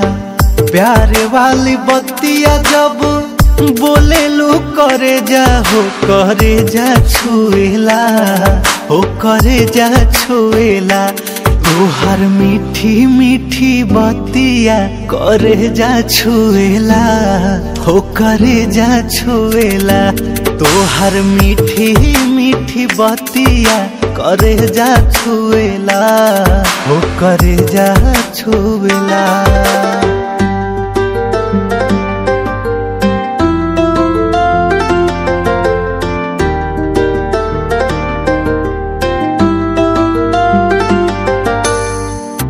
Bhojpuri Ringtones